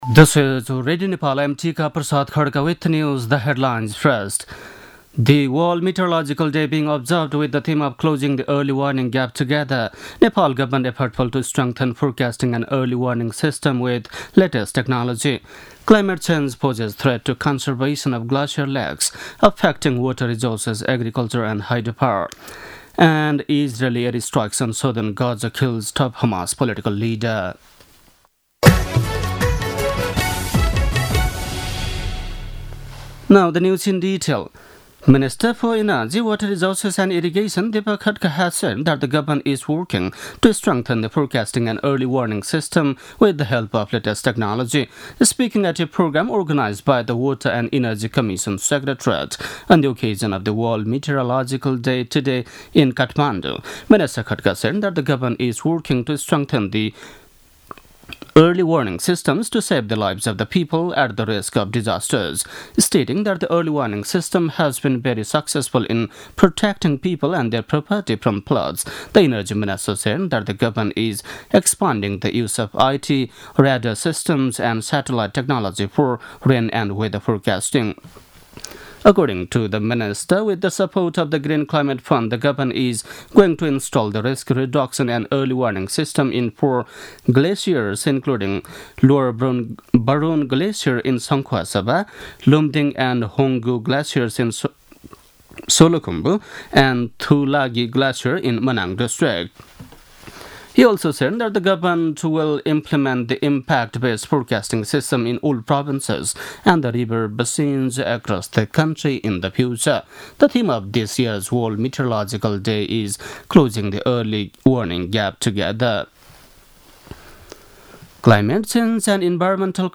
दिउँसो २ बजेको अङ्ग्रेजी समाचार : १० चैत , २०८१
2-pm-news-1-7.mp3